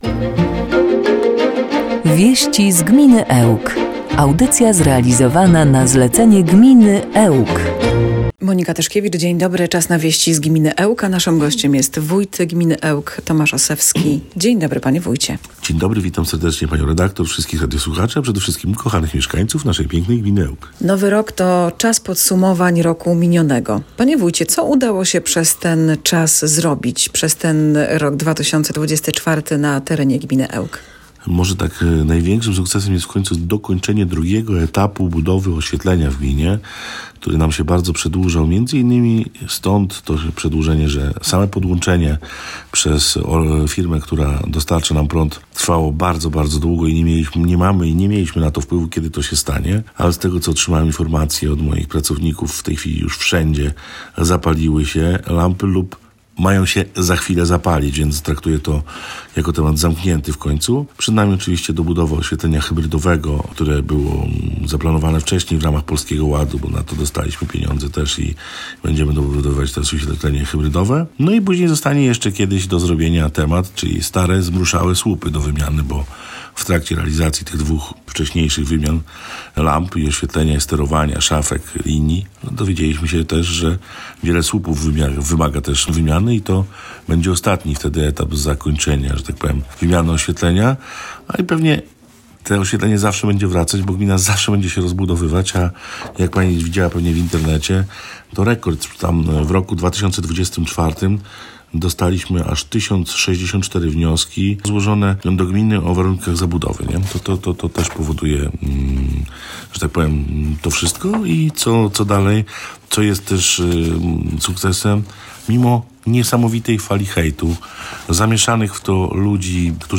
Gościem Radia 5 był Tomasz Osewski, wójt gminy Ełk.
10-01-WIESCI-Z-GMINY-ELK-Z-JINGLAMI.mp3